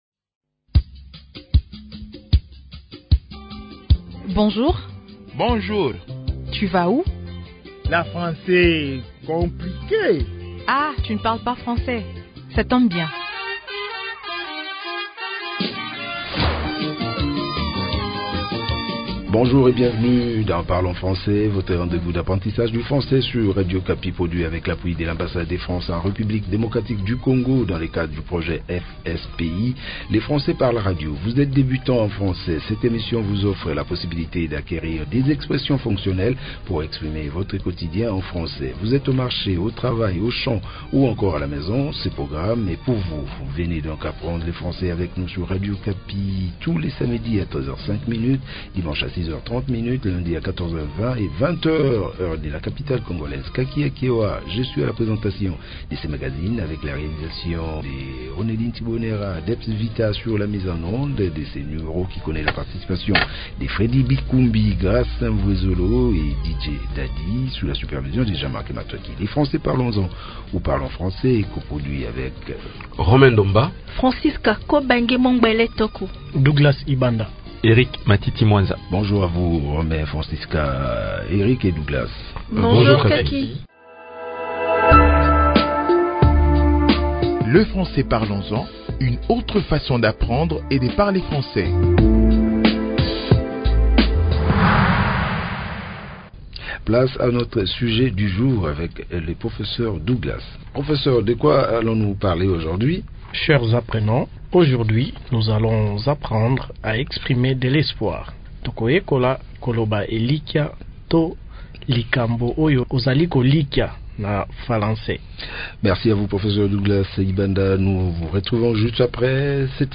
Merci de découvrir cette nouvelle leçon de notre programme Parlons Français.